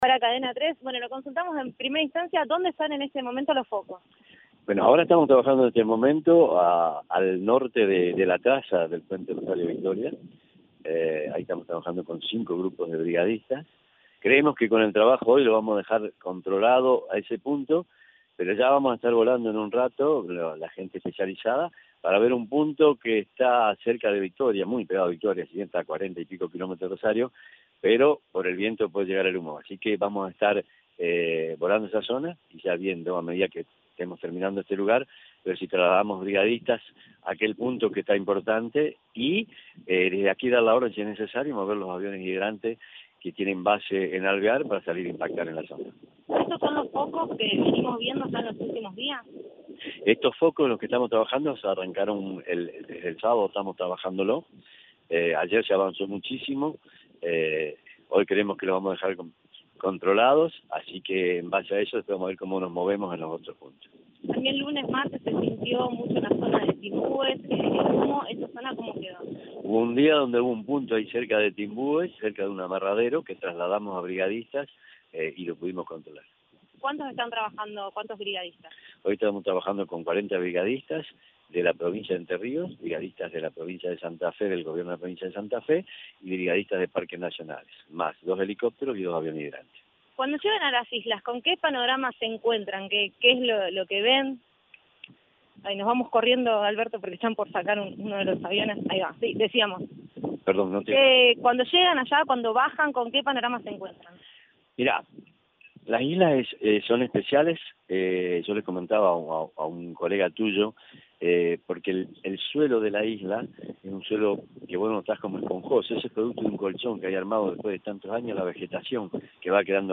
El secretario de Protección Civil de la provincia de Santa Fe, Roberto Rioja, contó al móvil de Cadena 3 Rosario, en Siempre Juntos, que los brigadistas que combaten los incendios en las islas suelen actuar antes del mediodía por una cuestión climática.